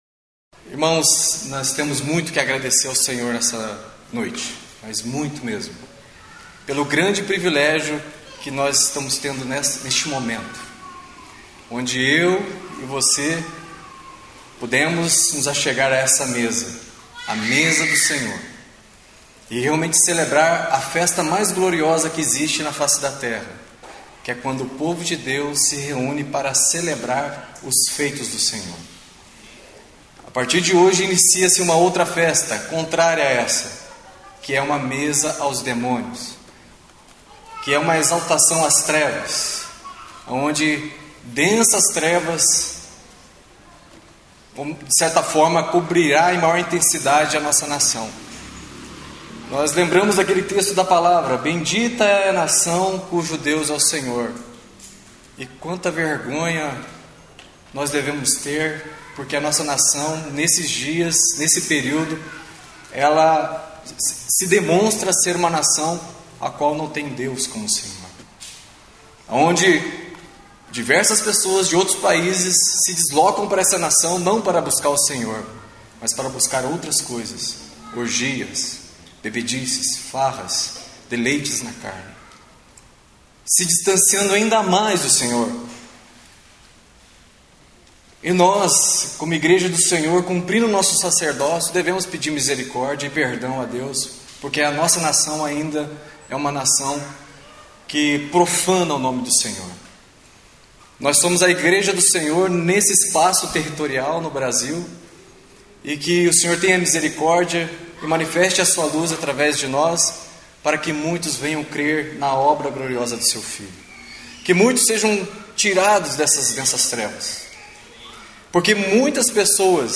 na reunião da igreja em Curitiba